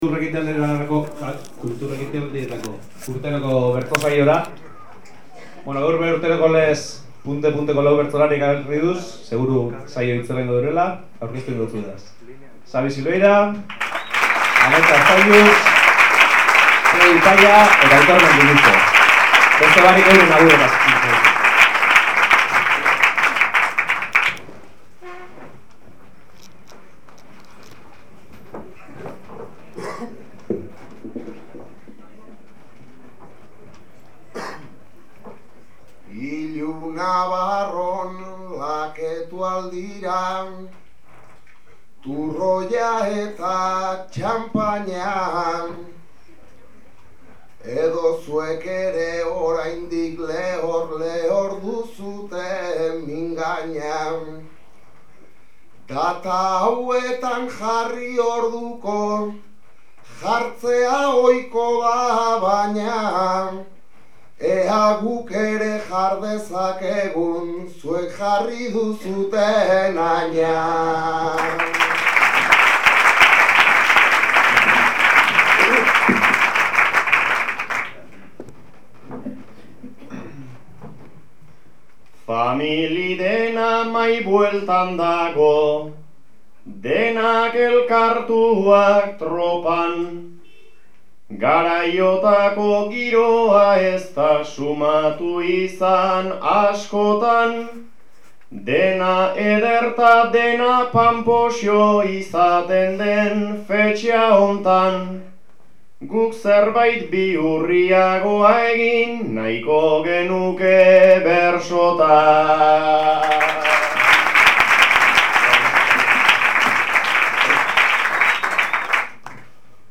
Lau bersolari ezagun.
Iluntzeko zazpiretarako ondo beteta zegoen Hori Bai.
Eskenatokitik kantuan hasi eta atoan girotu zen saioa.